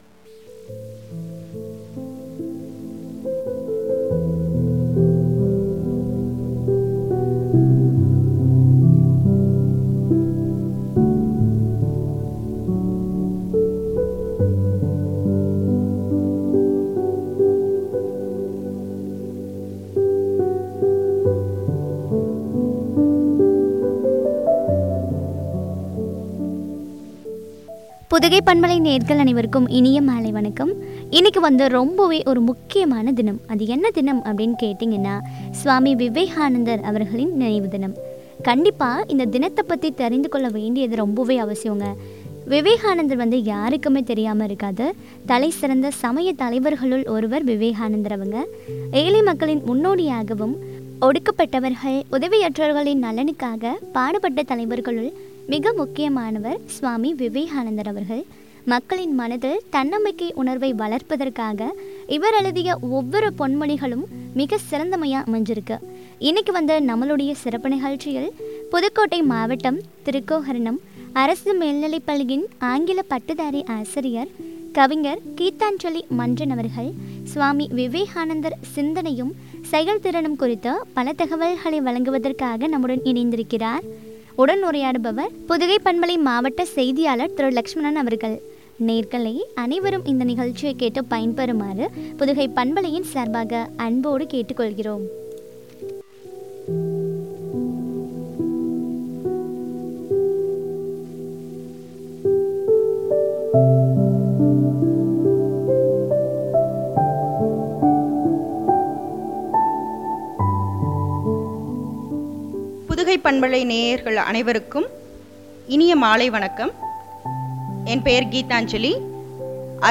செயல் திறனும்’’ குறித்து வழங்கிய உரையாடல்.